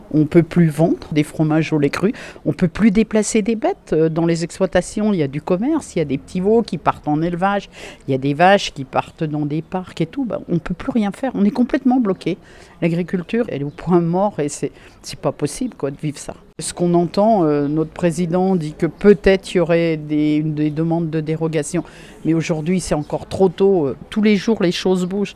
L’élue haut-savoyarde, agricultrice elle-même, évoque une catastrophe agricole en perspective et le conseil départemental prépare sa riposte et son soutien.